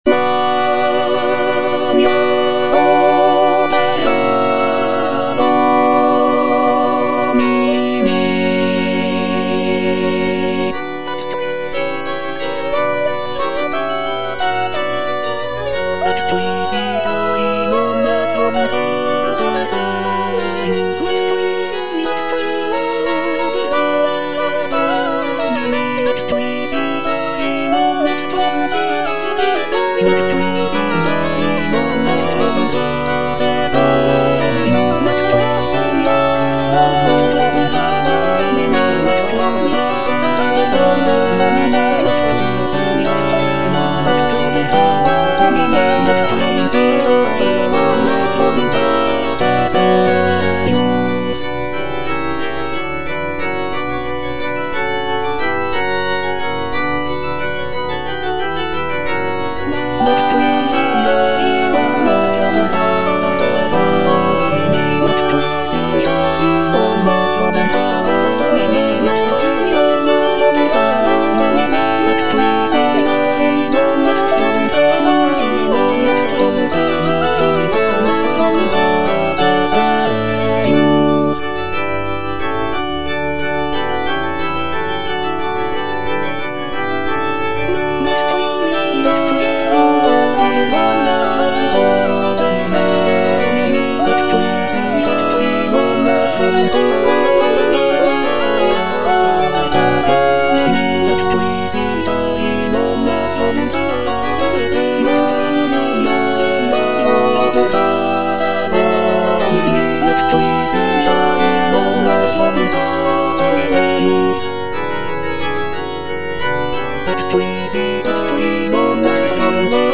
(All voices)
is a sacred choral work